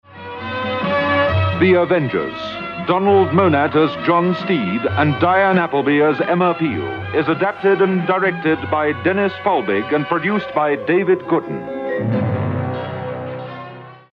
You know you're listening to a Springbok Radio programme by the distinctive voices of the announcers that can be heard at the top and tail of each episode...
This announcement was usually heard just once a week, ordinarily at the end of the Friday night transmission.